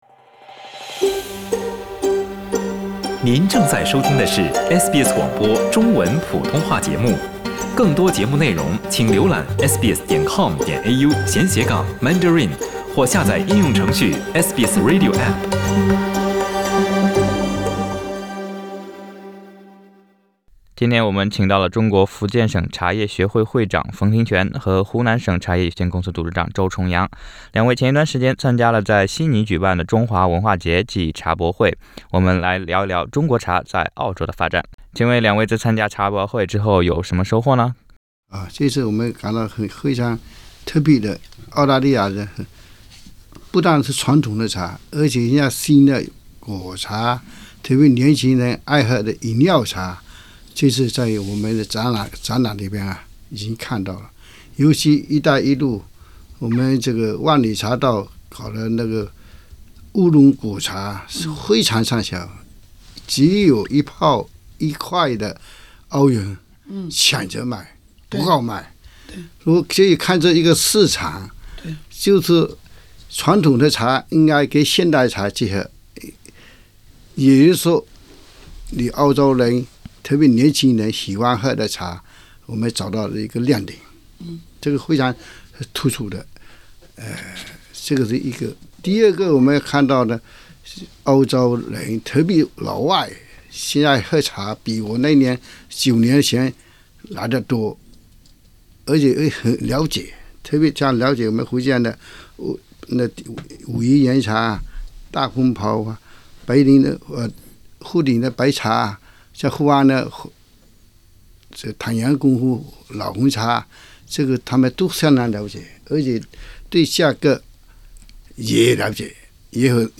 Source: Supplied SBS 普通话电台 View Podcast Series Follow and Subscribe Apple Podcasts YouTube Spotify Download (16.35MB) Download the SBS Audio app Available on iOS and Android 中国茶在澳洲：前景如何？